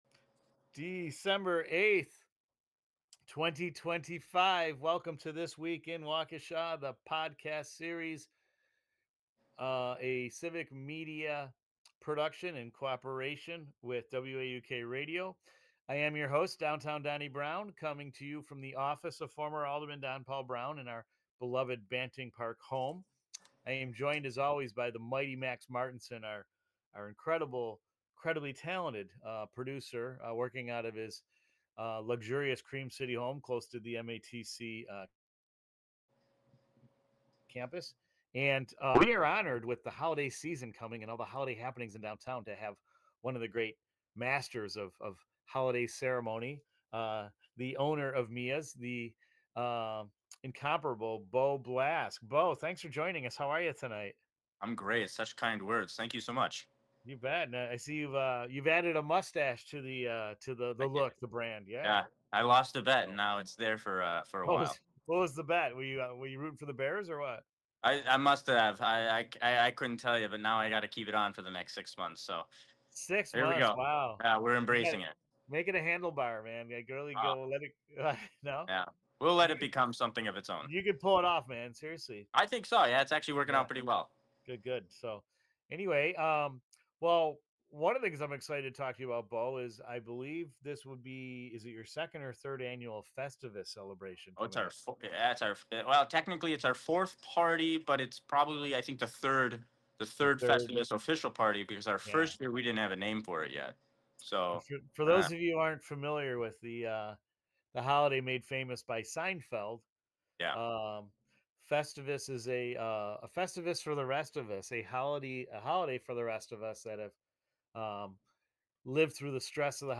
for a lively conversation about food, creativity, and festive traditions in downtown Waukesha.